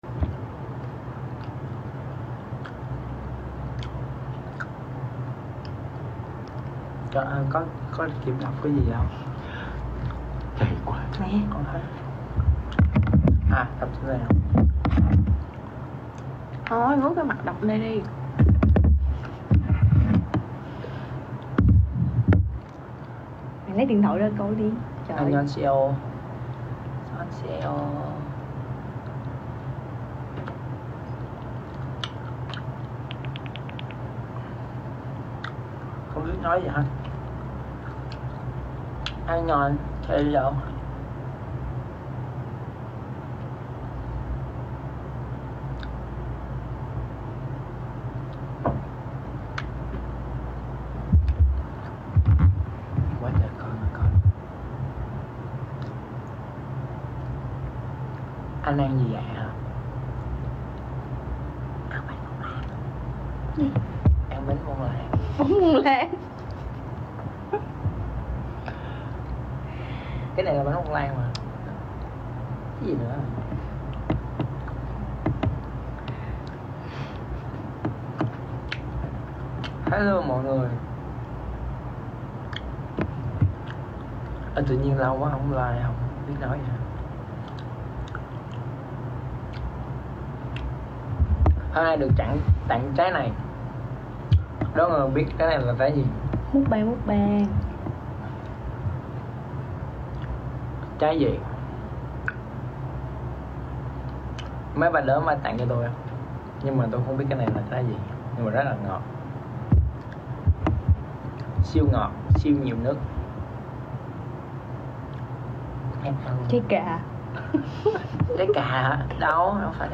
Full live stream của Jack sound effects free download